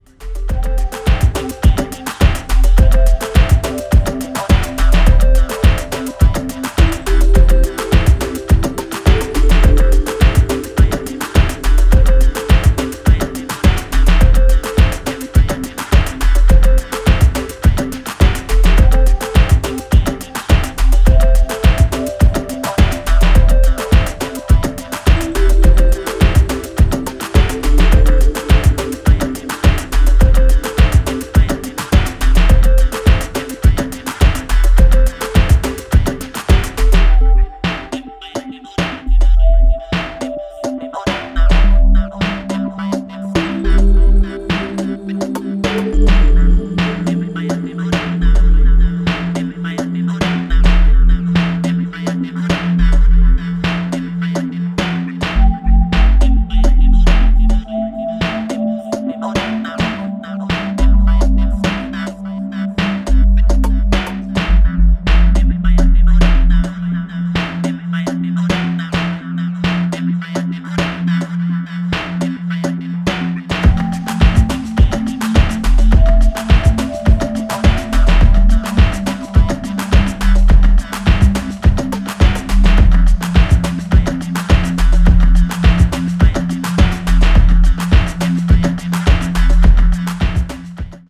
contemporary electronica